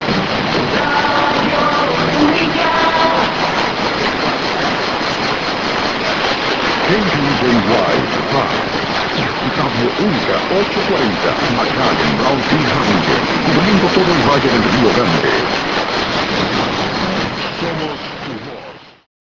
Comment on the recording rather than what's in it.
Radio Unica net and local ID at hour, into nx. Poor, but dominant.